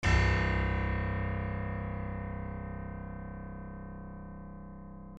piano-sounds-dev
c0.mp3